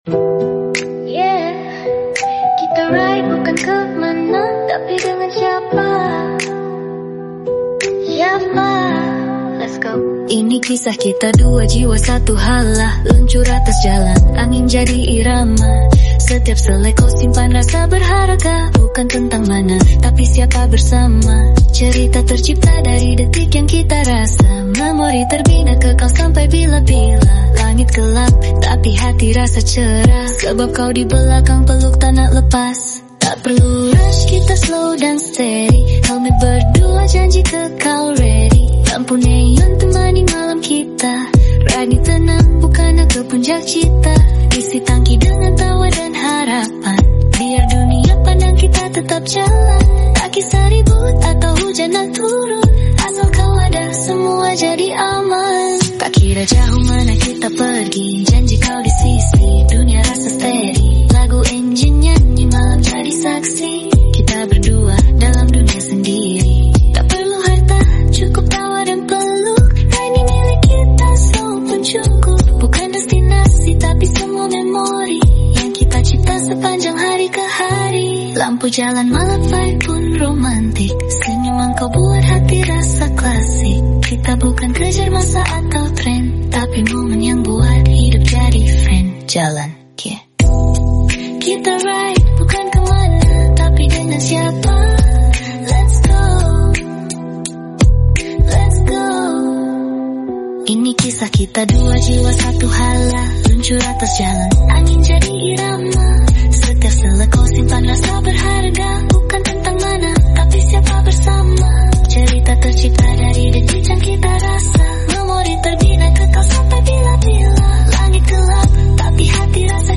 Genting Downhill Mp3 Sound Effect Part 1 : Genting Downhill with Forza 250.